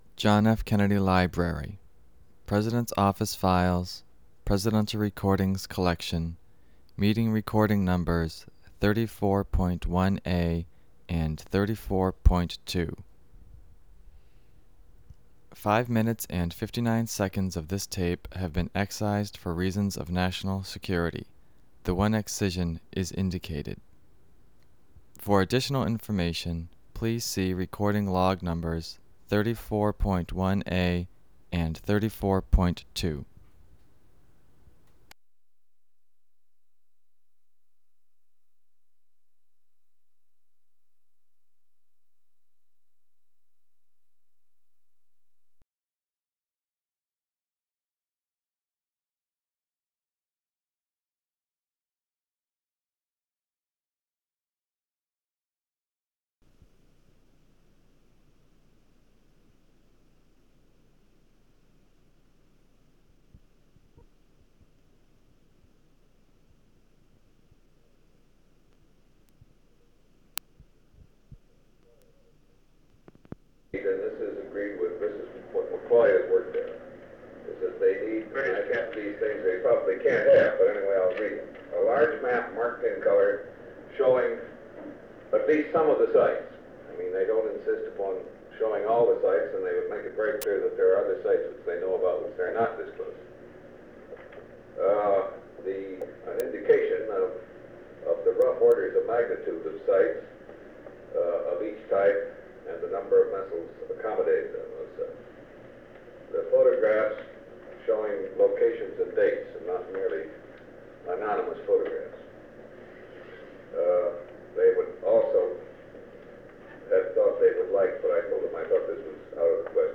Secret White House Tapes | John F. Kennedy Presidency Executive Committee Meeting of the National Security Council on the Cuban Missile Crisis (cont.)